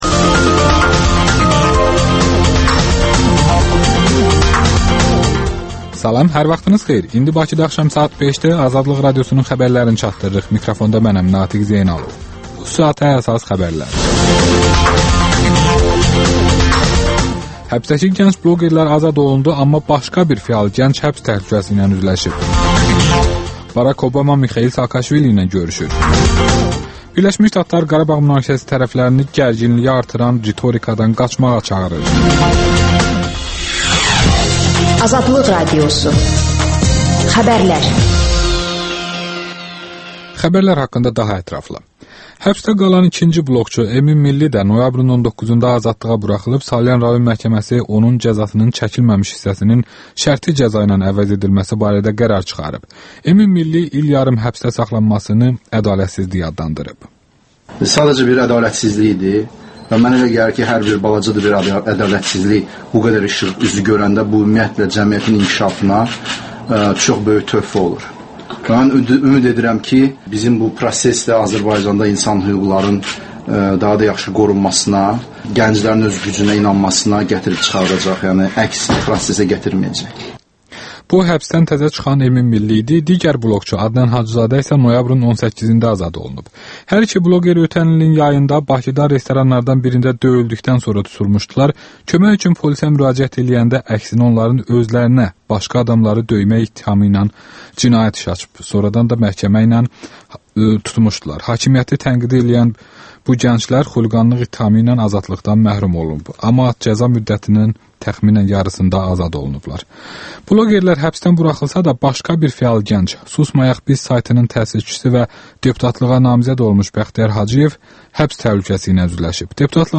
canlı efirdə